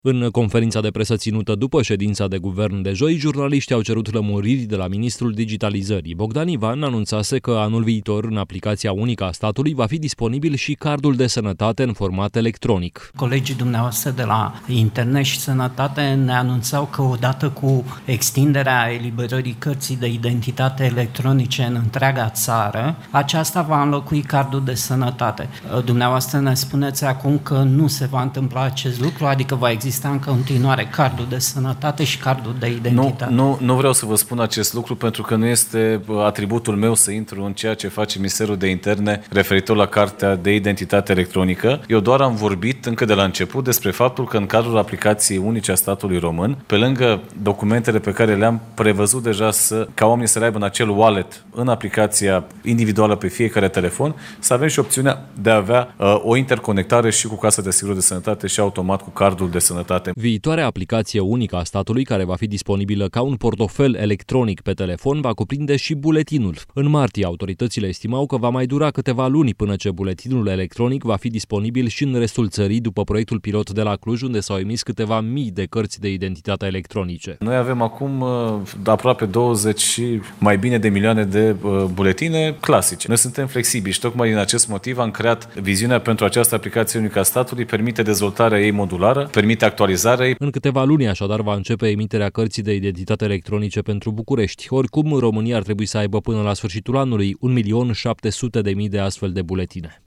Ministrul Digitalizării, Bogdan Ivan: „Nu este atributul meu să intru în ceea ce face ministerul de Interne referitor la cartea de identitate electronică”
În conferința de presă ținută după ședința de guvern de joi, jurnaliștii au cerut lămuriri de la ministrul Digitalizării.